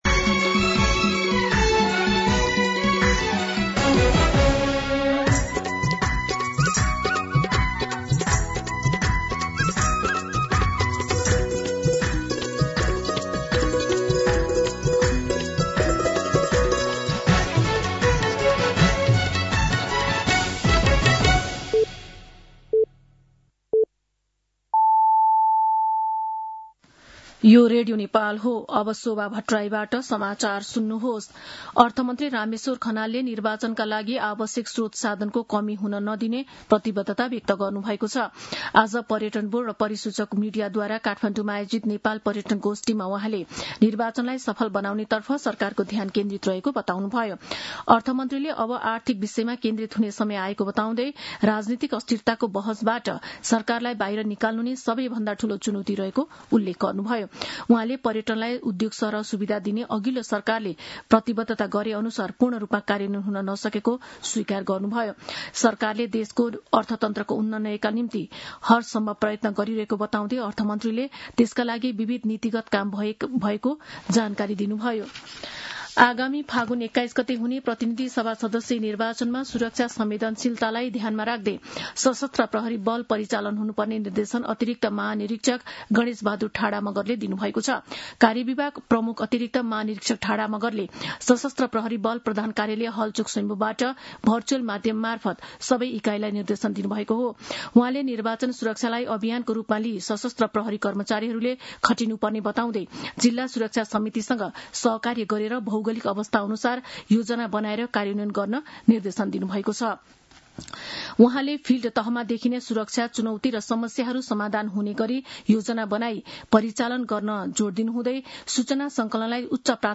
मध्यान्ह १२ बजेको नेपाली समाचार : ८ फागुन , २०८२
12pm-News-11-8.mp3